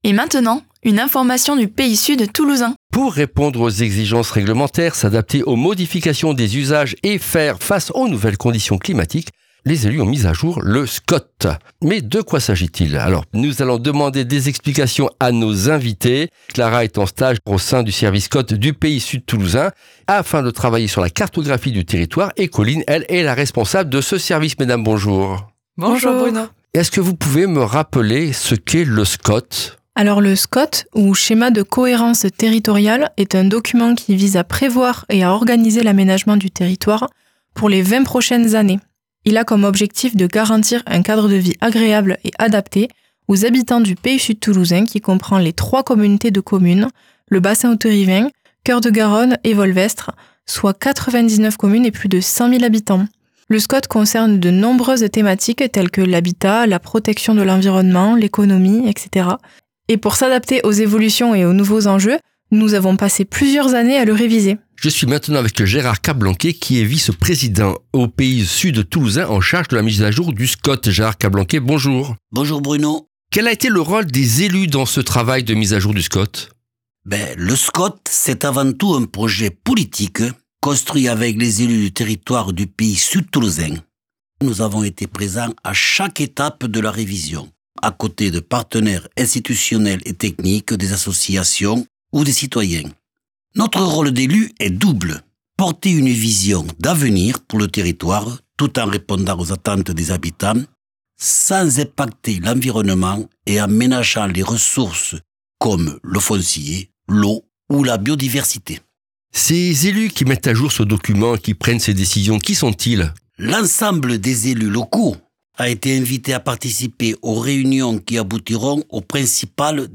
Retrouvez ici les brèves capsules radiophoniques de 5 min qui  servent d’introduction